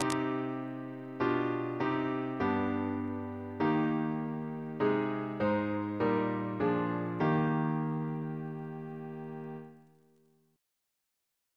Single chant in E♭ Composer: William Felton (1715-1769) Reference psalters: ACB: 147; H1940: 688; H1982: S420; OCB: 294; PP/SNCB: 109